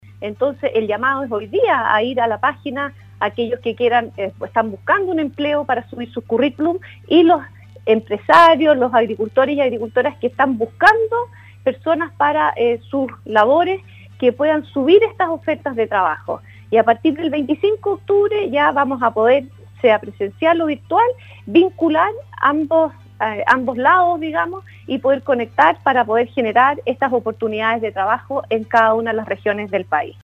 En entrevista con el Programa “Campo al Día” de Radio SAGO, la Ministra de Agricultura, María Emilia Undurraga, se refirió al actual escenario de déficit de mano de obra en el sector agropecuario, el cual en la región de Los Lagos alcanza a más del 25% y el asunto se agudizará en época de cosechas.